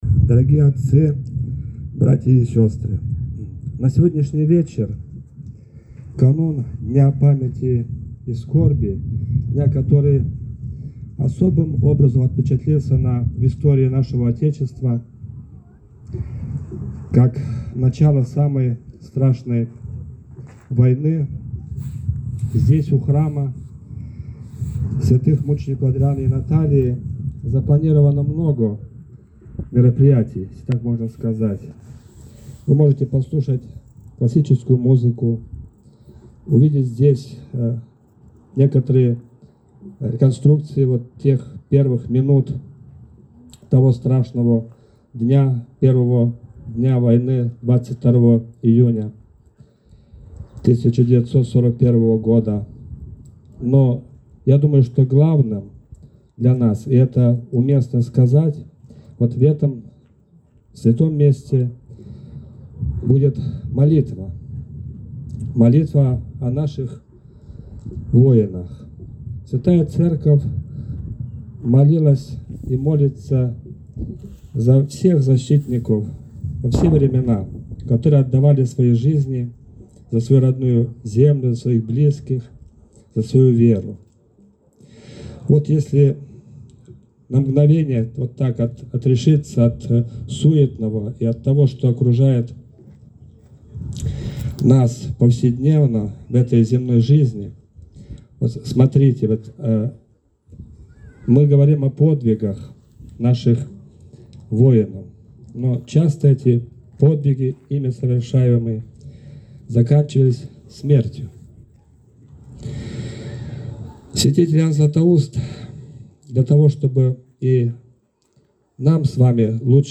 Обращение